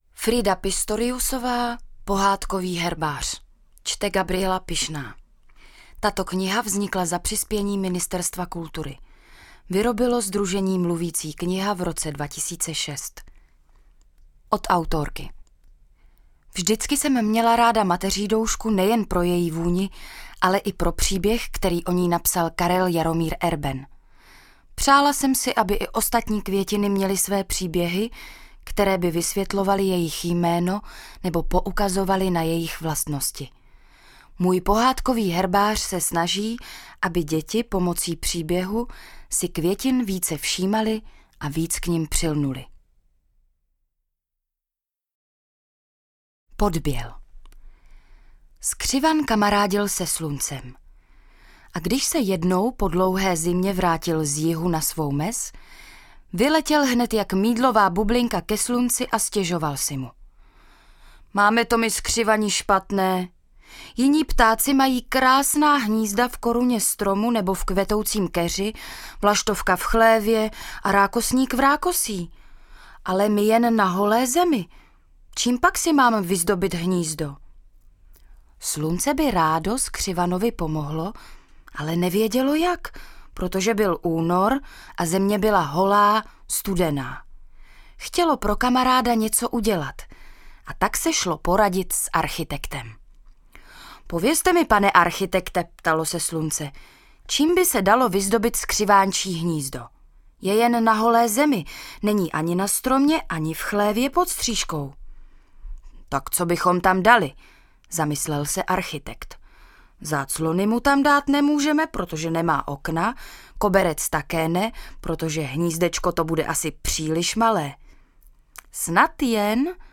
Pohádkové vyprávění o květinách inspirované mimo jiné Karlem Jaromírem Erbenem.